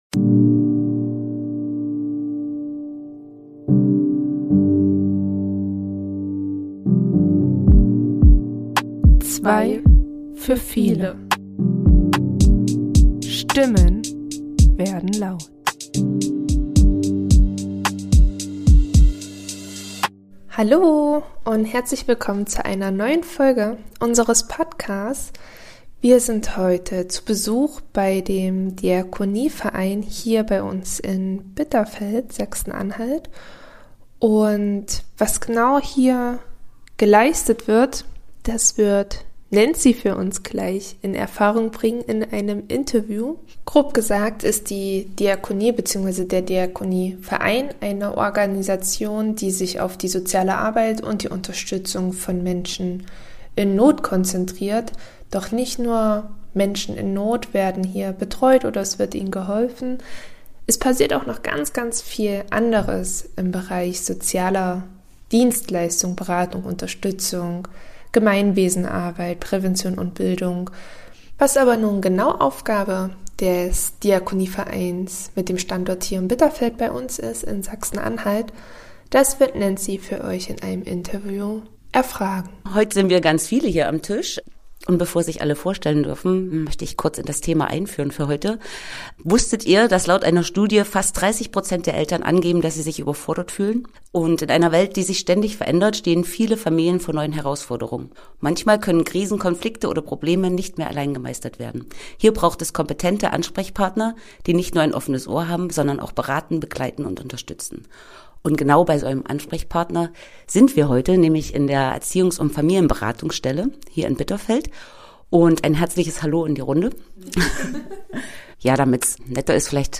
Im Interview teilen unsere Gäste Einblicke in ihre tägliche Arbeit, die oft im Verborgenen stattfindet.